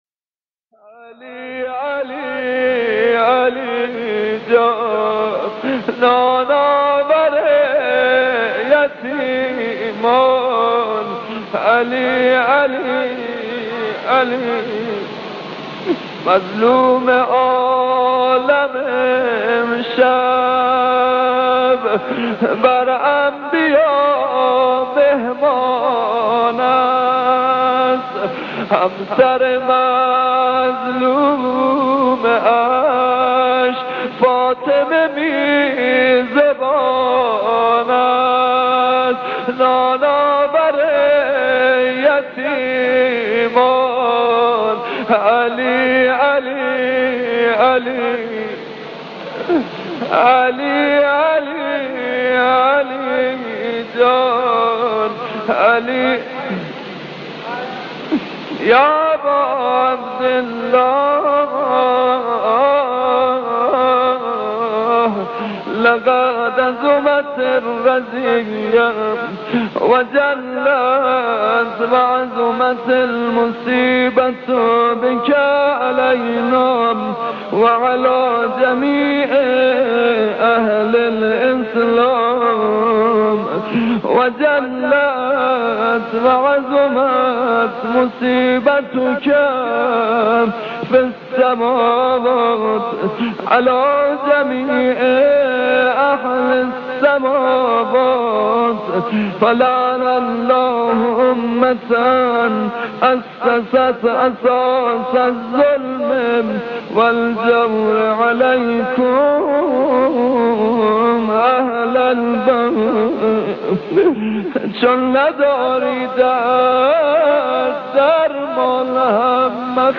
در پرده عشاق، صدای مداحان و مرثیه‌خوانان گذشته تهران قدیم را خواهید شنید که صدا و نفس‌شان شایسته ارتباط دادن مُحب و مَحبوب بوده است.
گریز از شعر توسل به امیرمومنان (ع) و اشاره به رنج‌های حضرت زینب (س) و خواندن بخش‌هایی از زیارت عاشورا